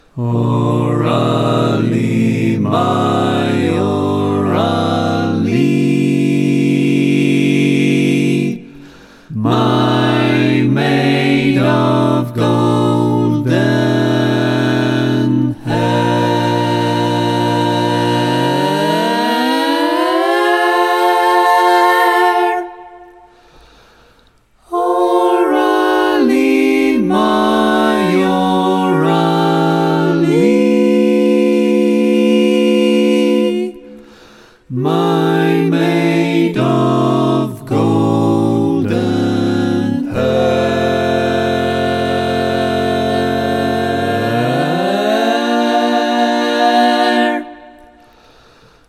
Key written in: B♭ Major
How many parts: 4
Type: Barbershop
2) Everytime you sing a note, it goes UP a half-step
3) Everytime you sing a note, it goes DOWN a half-step
All Parts mix:
Learning tracks sung by